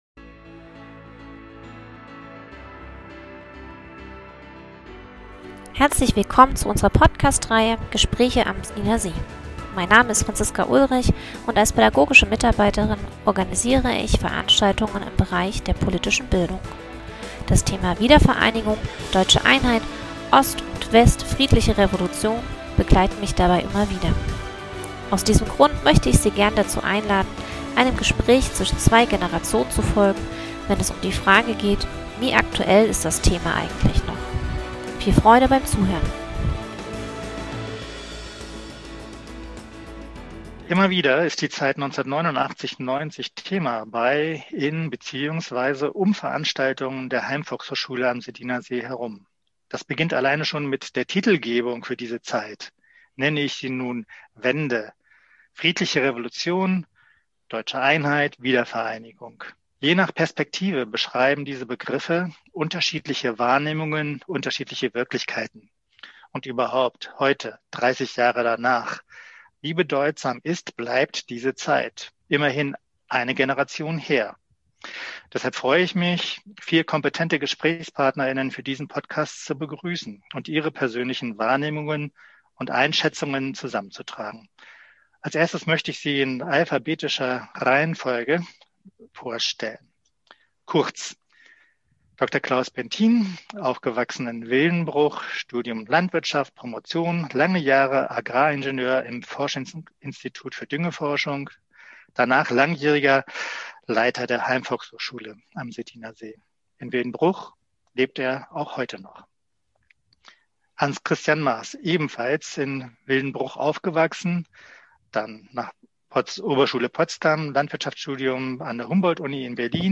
Podcast: Zwei Generationen im Gespräch